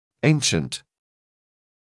[‘eɪnʃənt][‘эйншэнт]древний, старинный